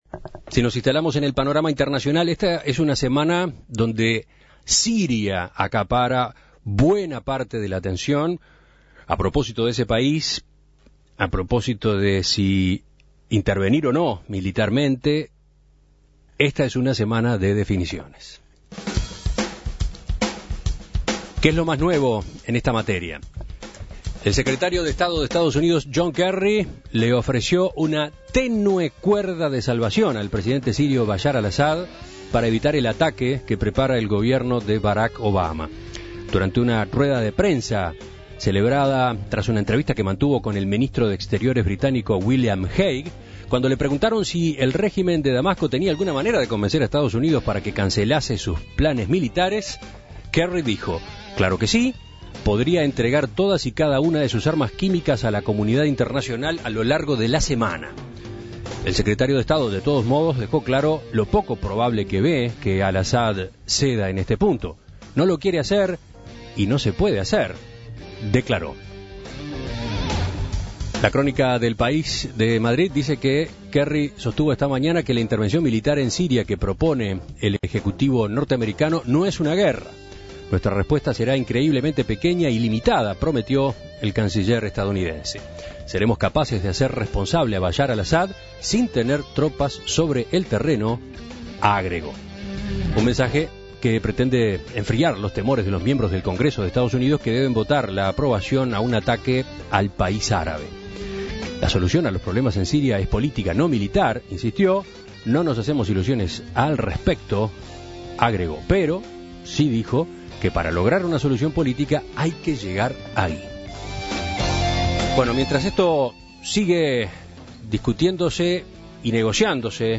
desde Turquía.